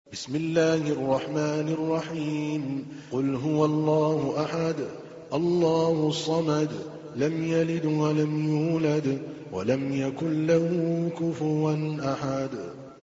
تحميل : 112. سورة الإخلاص / القارئ عادل الكلباني / القرآن الكريم / موقع يا حسين